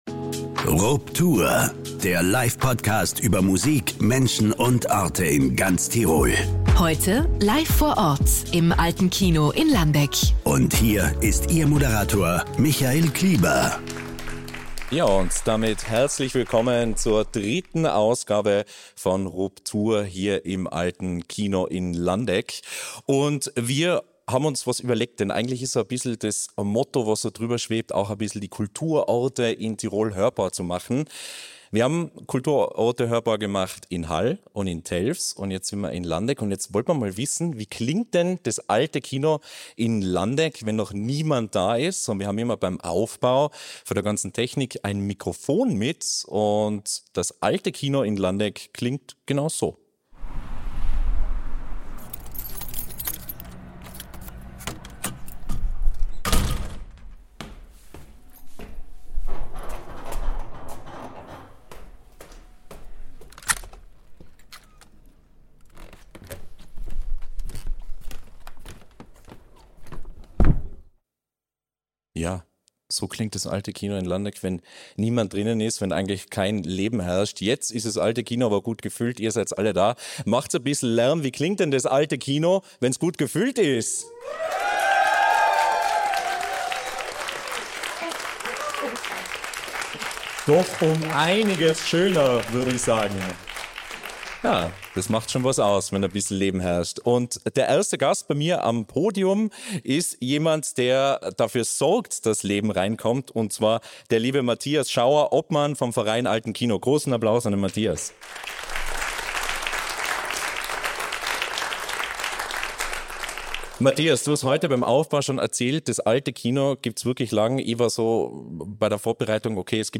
Tirols Livepodcast über Musik, Menschen und Orte zu Gast im alten Kino Landeck.